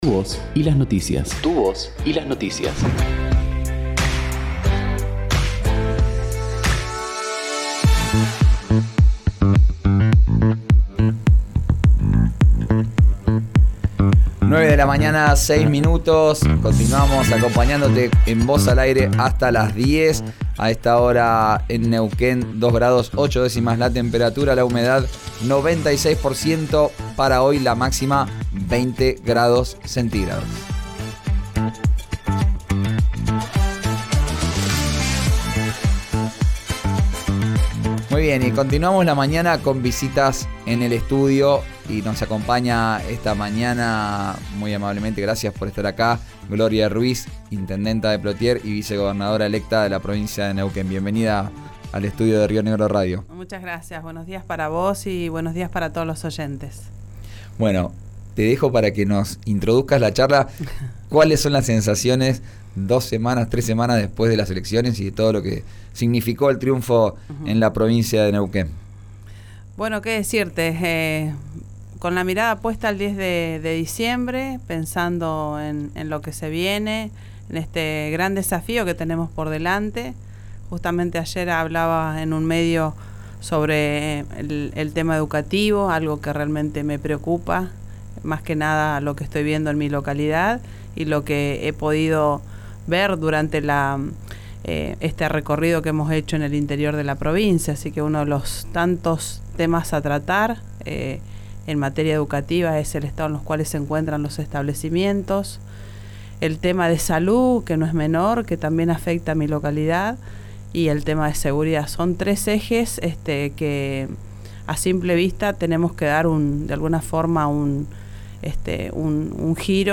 La intendenta de Plottier y vicegobernadora electa de la provincia, Gloria Ruiz, se refirió al gabinete que están armando junto a Rolando Figueroa para el gobierno que comenzarán el 10 de diciembre y reveló que ya hay algunos nombres, pero aún «no se pueden decir». Evaluó que el tiempo disponible para hacer la transición «es una ventaja» y anticipó sus principales preocupaciones, durante una entrevista con RÍO NEGRO RADIO.